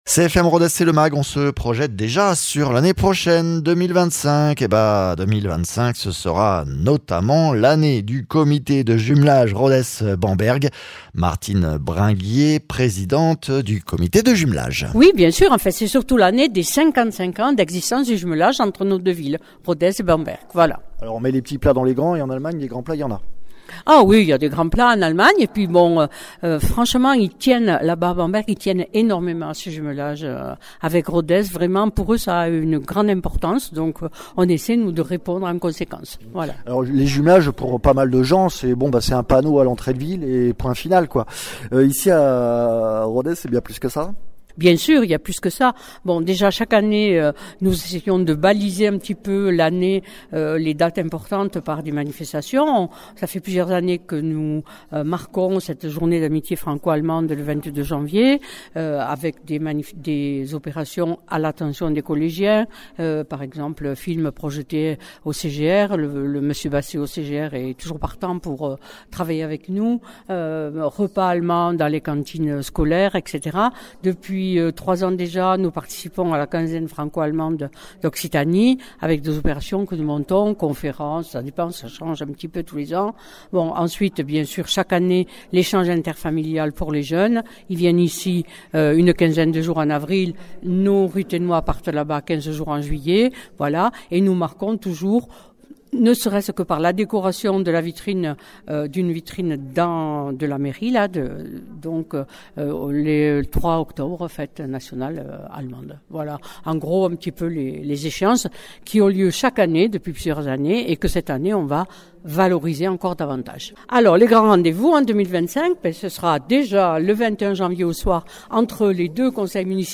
Interviews
Invité(s) : Régine Taussat, adjointe à la mairie de Rodez